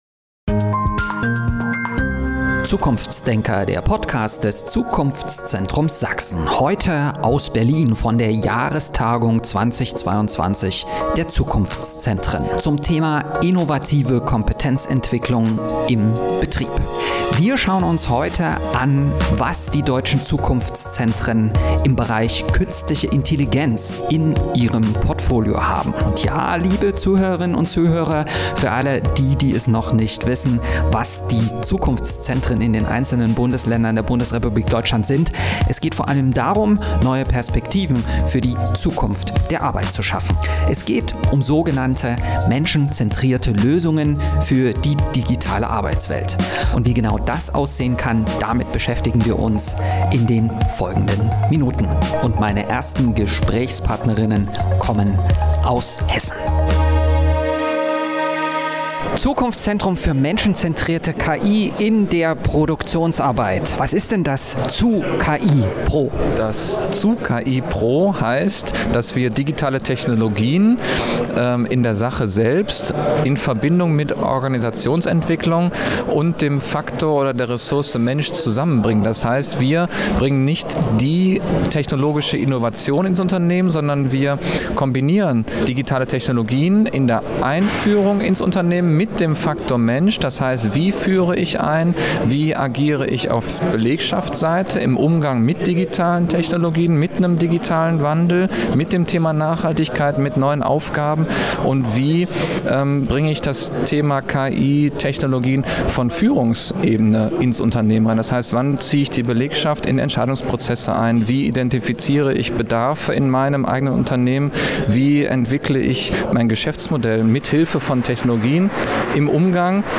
Die Zukunftszentren in Deutschland unterstützen Unternehmen genau auf diesem Weg mit einer menschenzentrierten Perspektive. Zur Jahrestagung der Regionalen Zukunftszentren im November 2022 in Berlin haben wir uns beim Zukunftszentrums Süd für Bayern und Baden-Württemberg, beim ZUKIPRO Hessen und beim RZzKI Saarland und Rheinland-Pfalz umgehört und umgesehen, wie die Unterstützung für Unternehmen konkret aussieht.